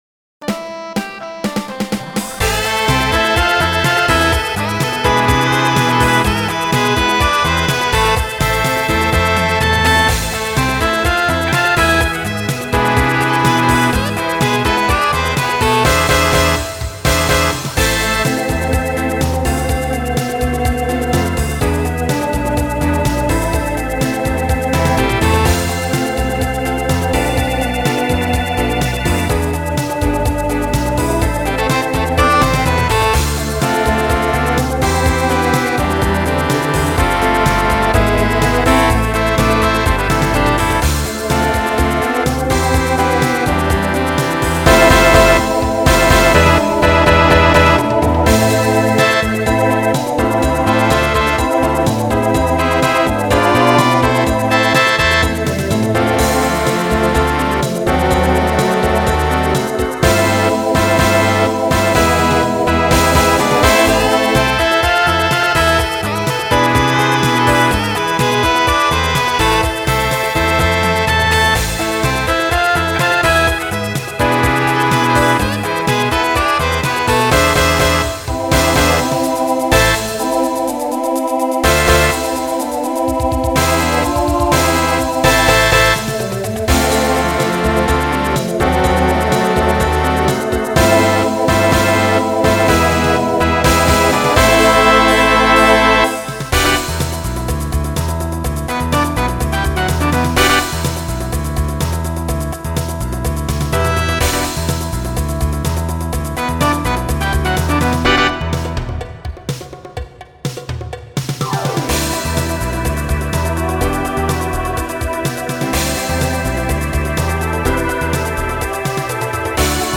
TTB/SSA
Voicing Mixed Instrumental combo
Latin , Pop/Dance